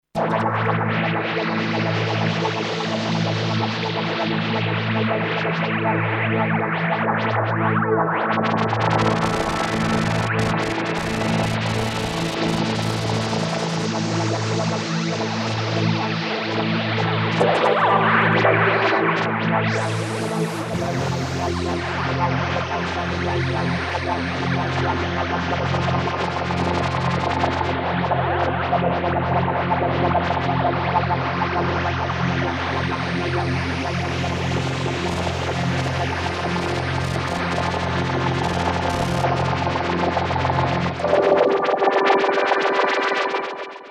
Digital rack effect full of choruses and complex modulation effects including famous Roland RSS dimensional, a proprietary technology based on psychoacoustic algorithms.
demo4 preset